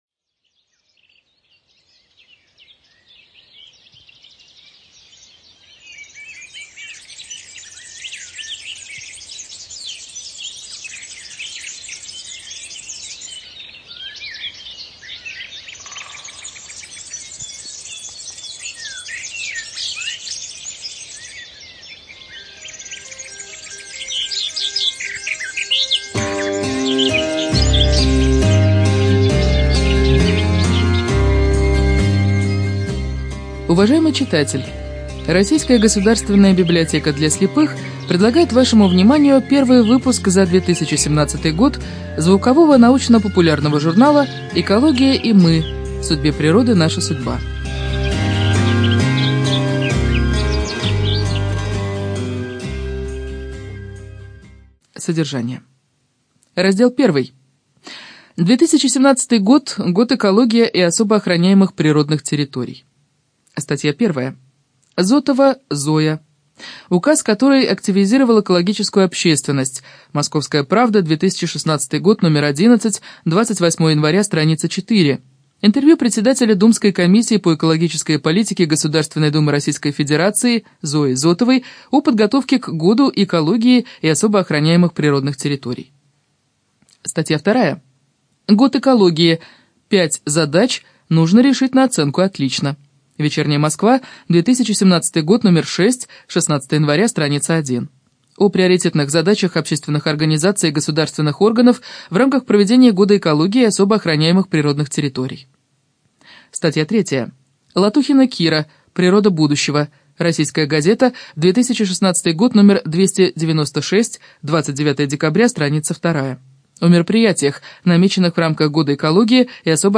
Студия звукозаписиРоссийская государственная библиотека для слепых